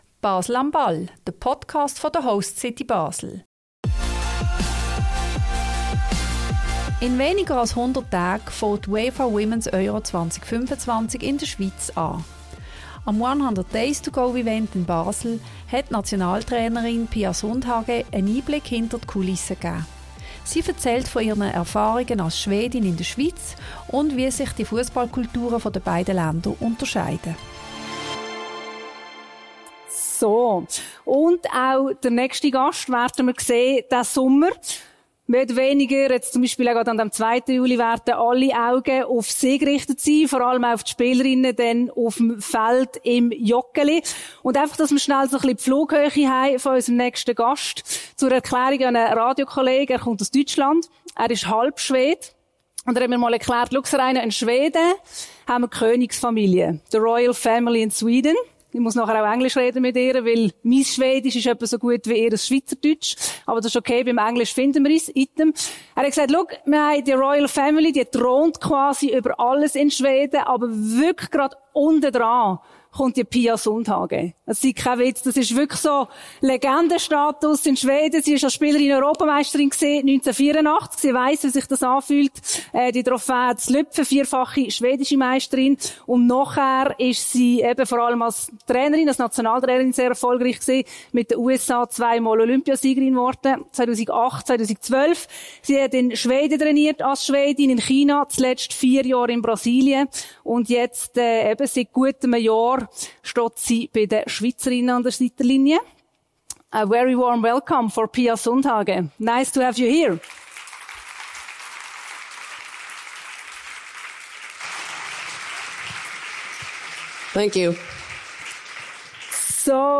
Am «100 Days To Go»-Event in Basel gab Nationaltrainerin Pia Sundhage einen Einblick hinter die Kulissen.